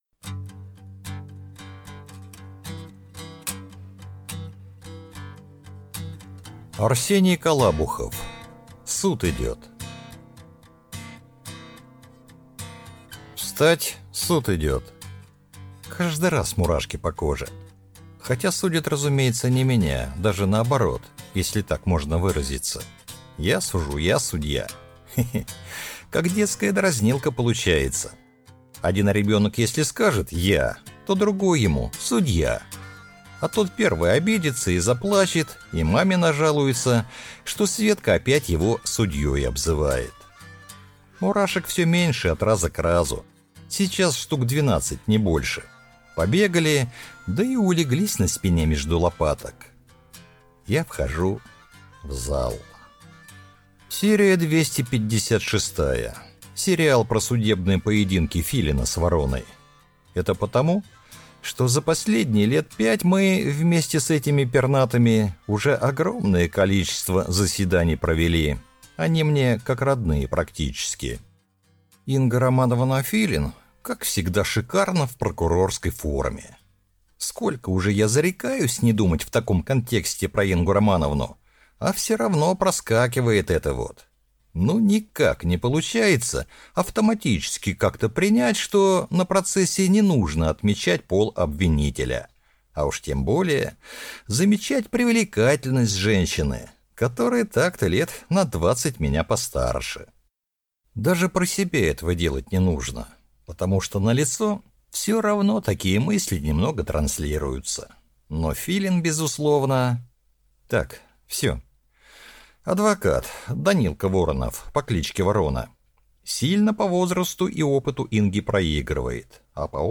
Аудиокнига Суд идёт | Библиотека аудиокниг